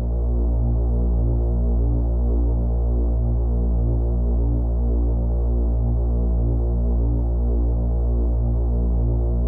rechargeBattery.wav